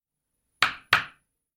铅笔打纸
描述：一支铅笔打了一次纸
Tag: 命中 铅笔